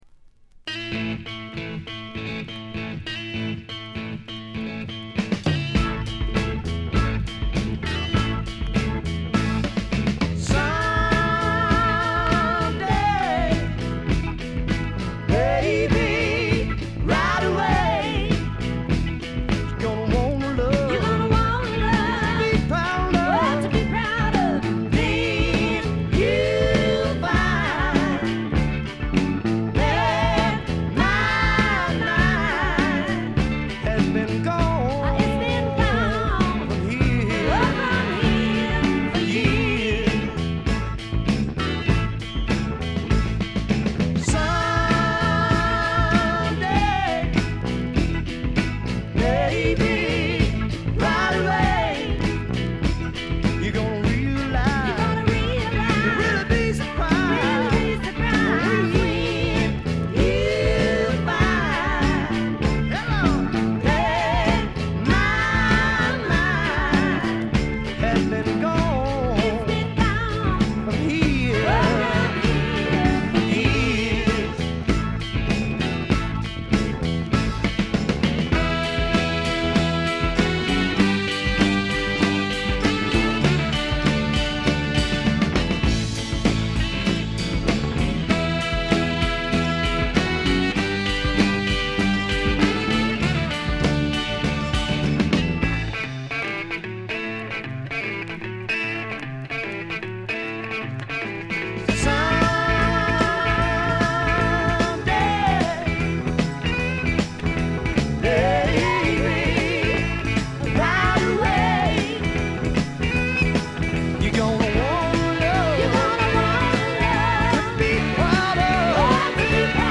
ところどころで軽微なチリプチ。
まさしくスワンプロックの原点ともいうべき基本中の基本盤。
試聴曲は現品からの取り込み音源です。